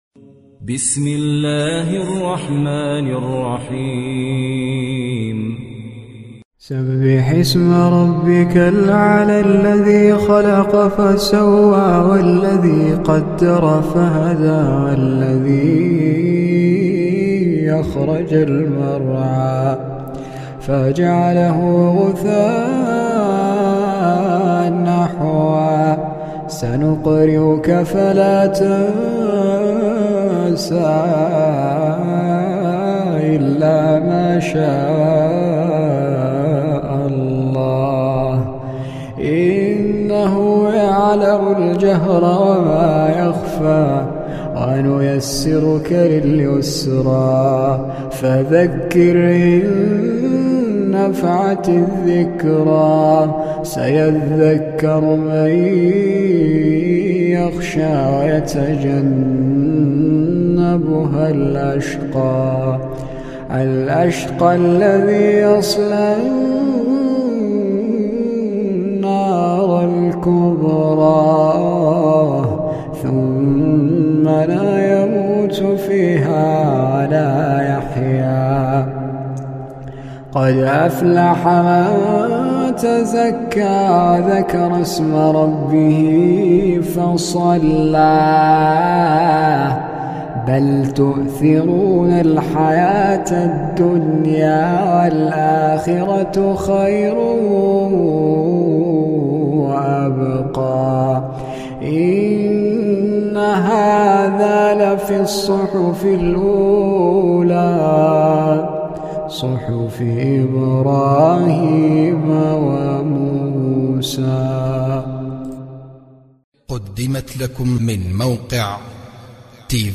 إلى جانب ذلك فهو يتمتع بصوت عذب رقيق يجعل صوته تخشع له القلوب وهو صوت لا مشابه له بين القراء.